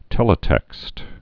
(tĕlĭ-tĕkst)